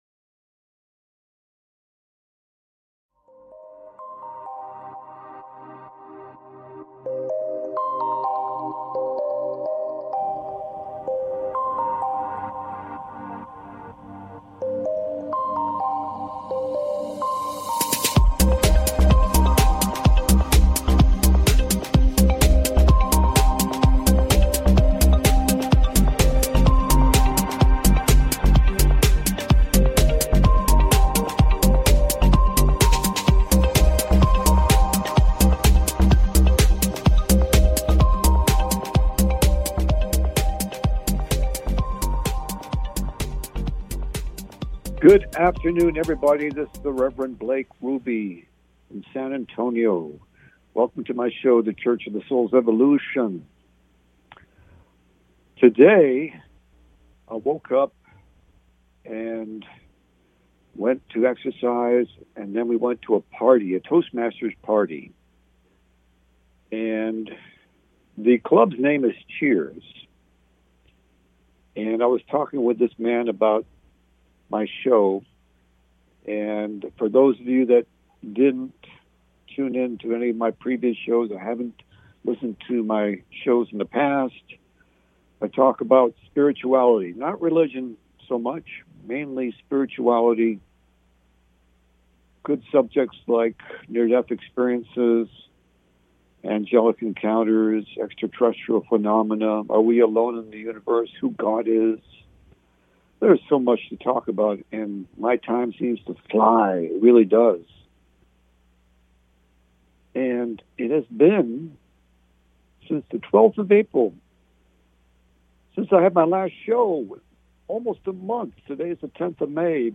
The Church of the Souls Evolution Talk Show